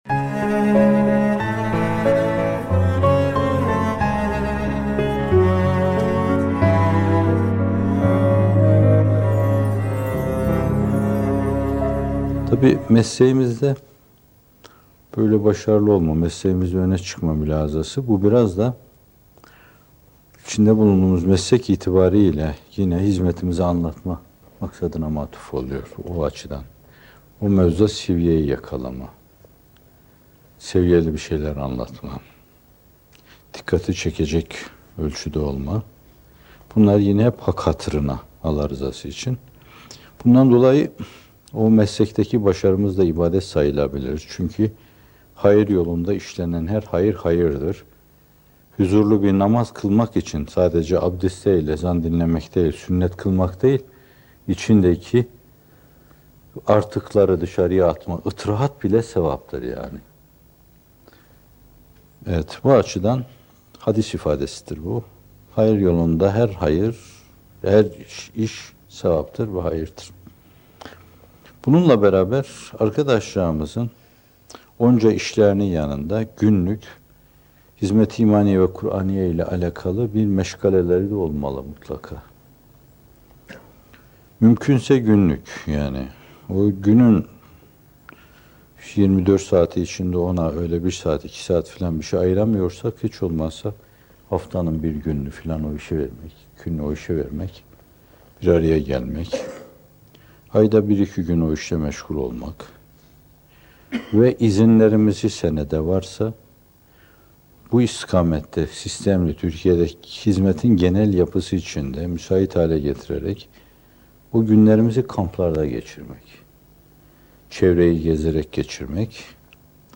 Neşriyat ile İştigal ve Gaye Eksenli Yol Haritası - Fethullah Gülen Hocaefendi'nin Sohbetleri